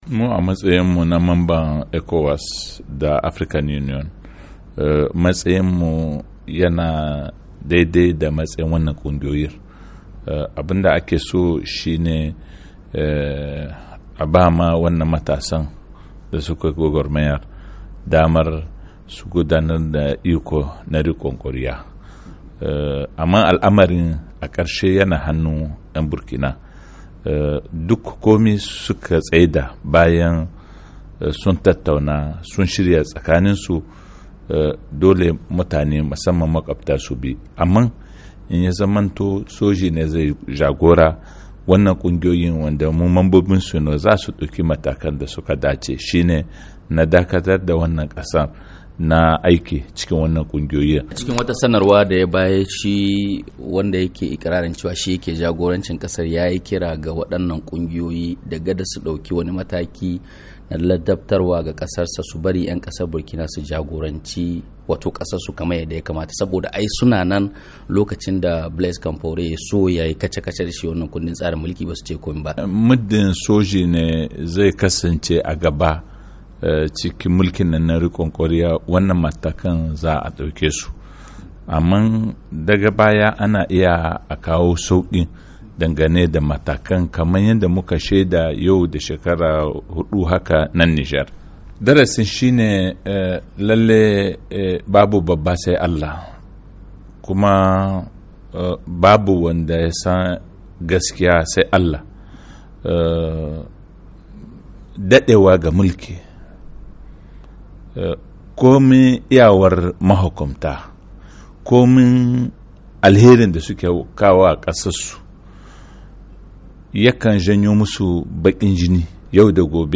Cikin firar da yayi da Muryar Amurka ministan harkokin wajem kasar Nijer Bazoum Muhammed yace wasu shugabannin kasashen Afirka dake shirin ta zarce yakamata abunda ya faru a Burkina Faso ya zama masu daratsi